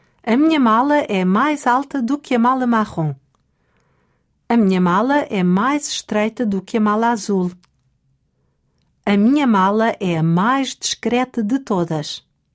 Ouça a conversa e descubra de quem é a mala de quem. Lytt til samtalen og finn ut hvem som eier hvilken koffert.